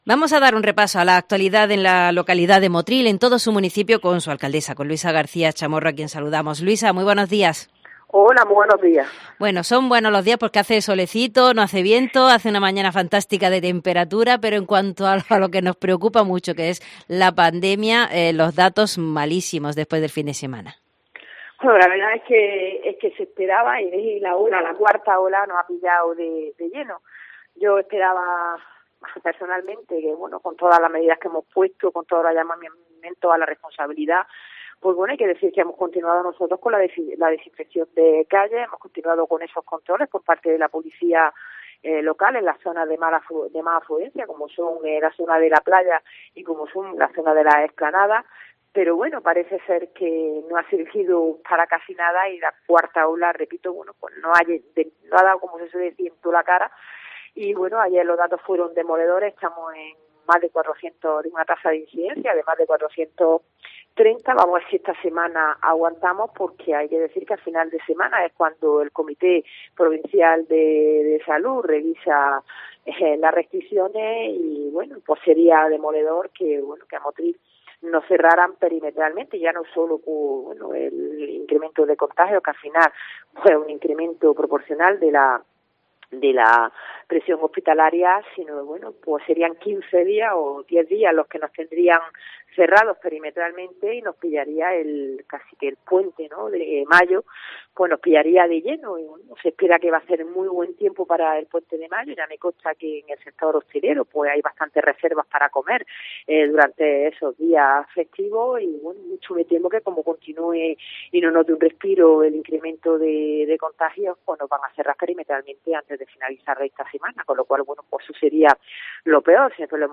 Un martes más repasamos la actualidad de Motril con la alcaldesa, Luisa García Chamorro, quien se muestra preocupada por los últimos datos de contagios de coronavirus en Motril.